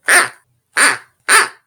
Listen to Sheepy bleating
SheepyBleating.mp3